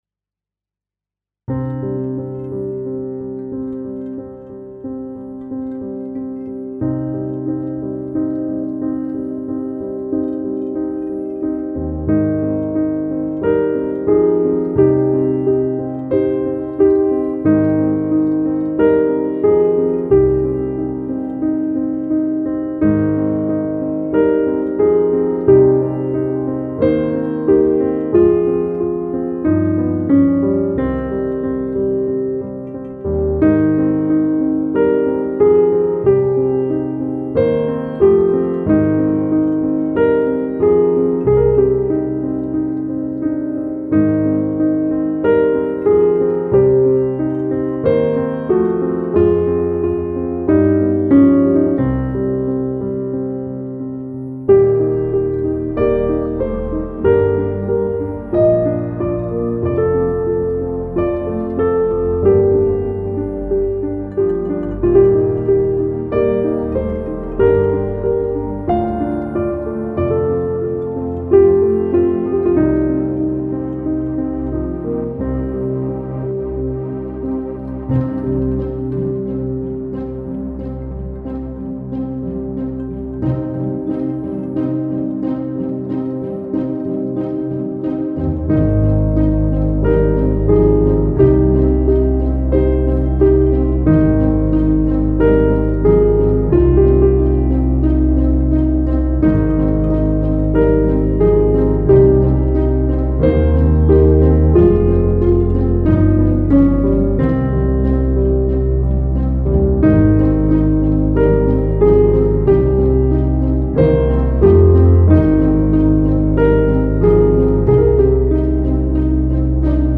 aerien - nostalgique - calme - melancolie - piano